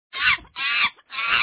chimpanzee.wav